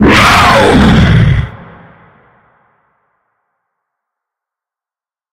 sounds / monsters / lurker / die_1.ogg